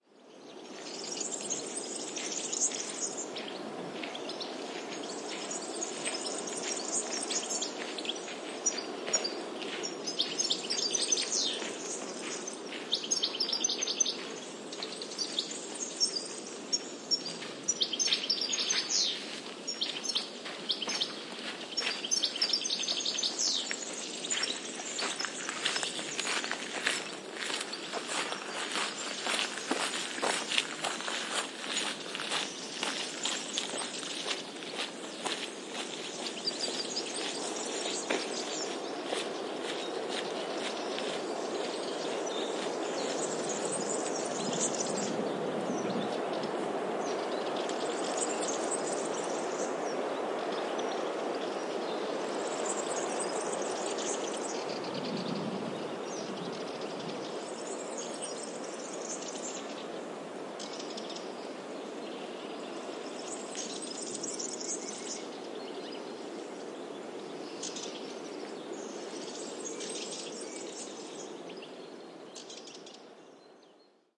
描述：在粗糙的沙滩上的脚步声，森林氛围在背景中包括鸟叫和树上的风。 Audiotechnica BP4025融入SD MixPre3
Tag: 鸟类 脚步声 南西班牙 气氛 性质 现场录音